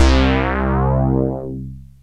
BIGBASS1.wav